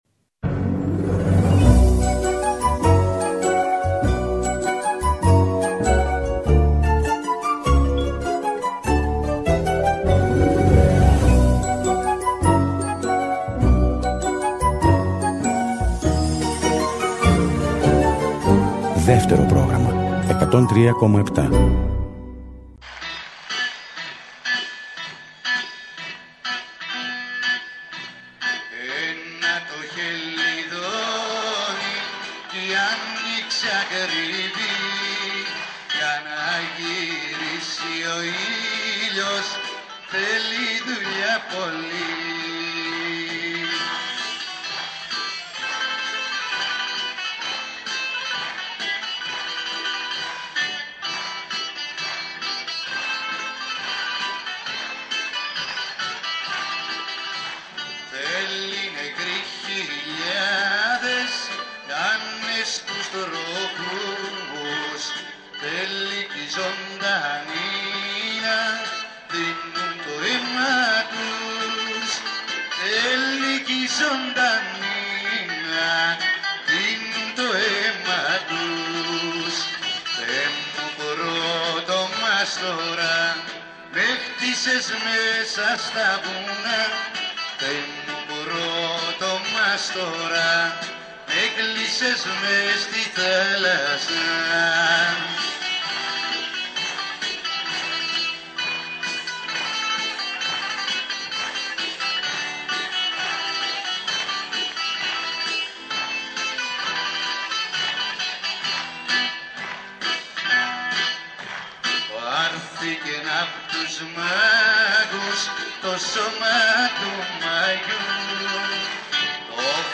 ζωντανά από το studio του Δεύτερου